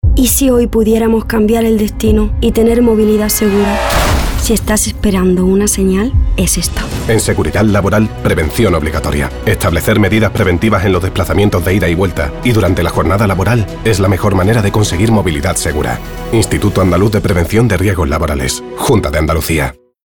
Cuña de radio de la campaña